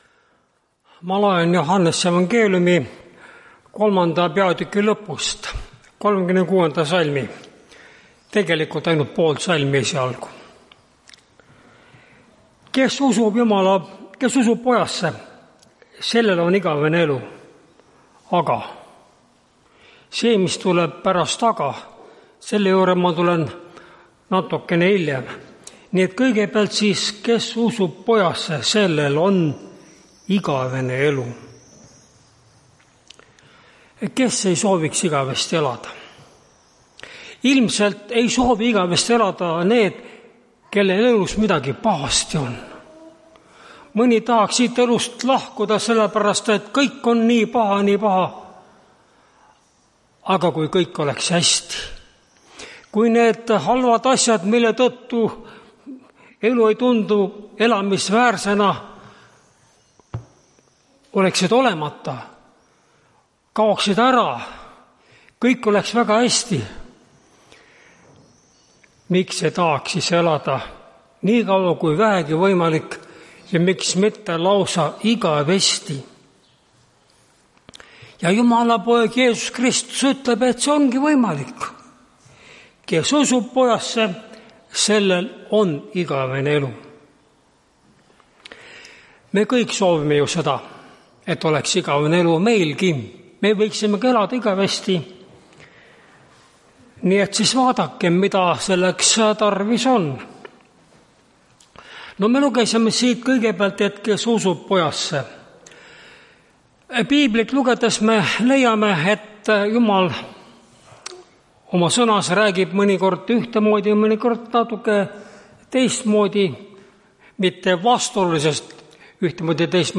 Tartu adventkoguduse 13.07.2024 hommikuse teenistuse jutluse helisalvestis.